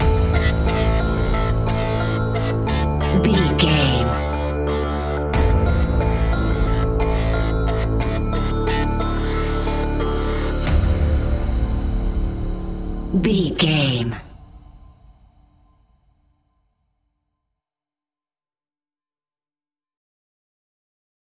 Aeolian/Minor
D
scary
tension
ominous
dark
haunting
eerie
synthesiser
drums
ticking
electronic music
electronic instrumentals